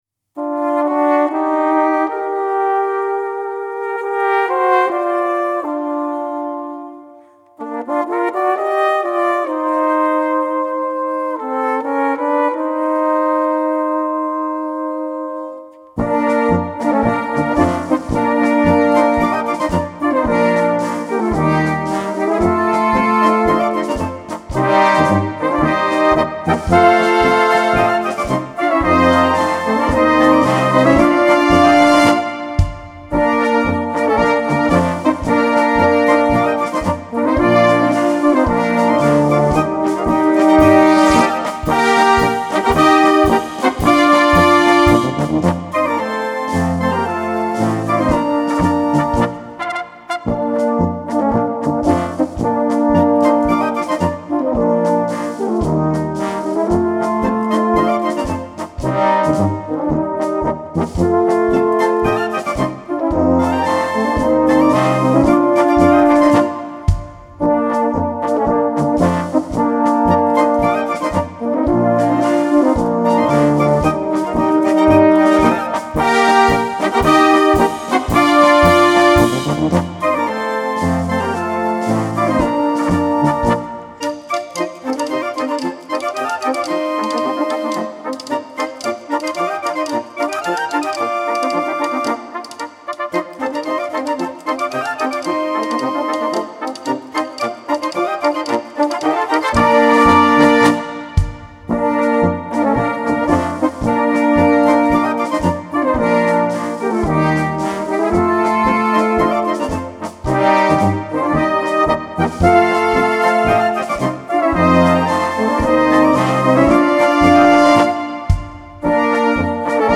Gattung: Polka
A4 Besetzung: Blasorchester Tonprobe